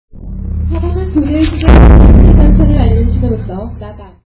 Beats on this massive drum signal the different stages of the changing of the guard (
drum.mp3